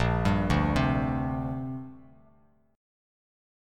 A#6add9 chord